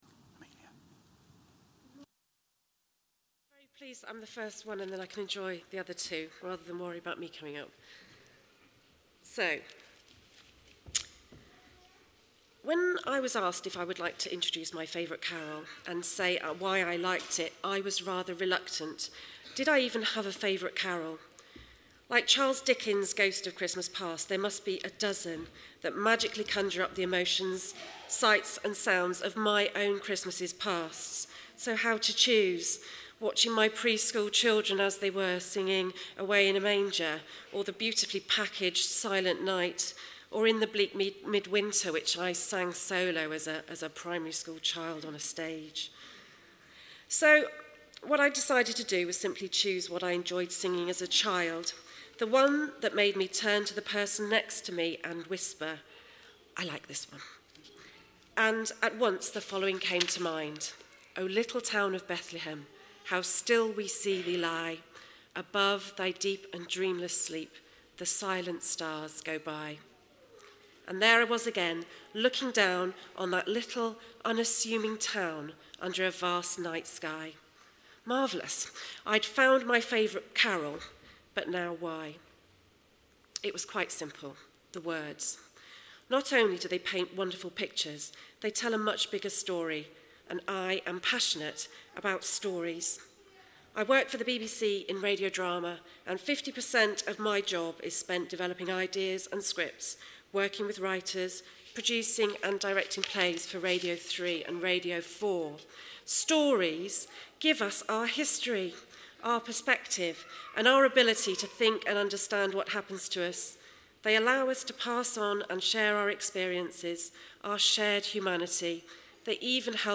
7 o’clock service
O Little Town of Bethlehem - congregation
Carol Services